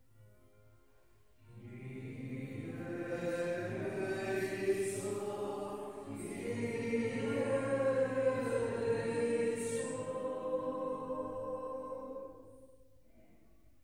Pregària de Taizé a Mataró... des de febrer de 2001
Convent de la Immaculada - Carmelites - Diumenge 19 de desembre de 2021